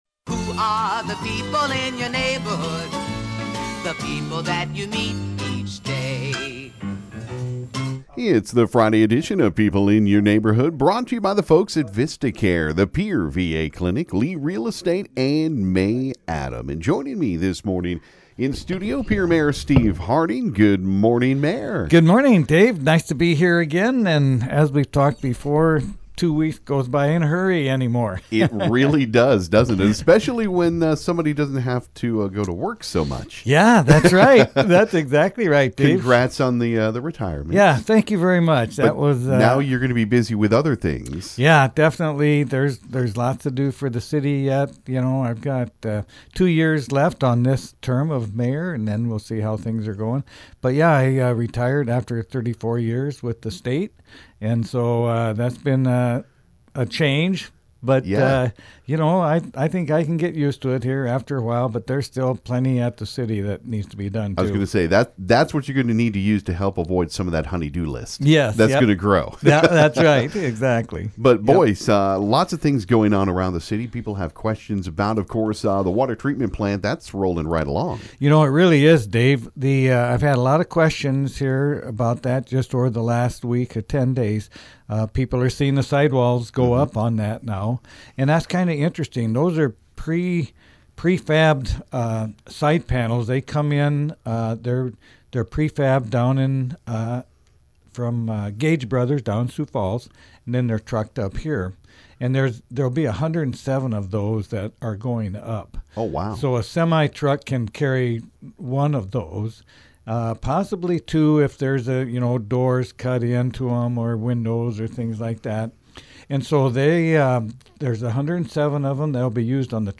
Pierre Mayor Steve Harding stopped by to talk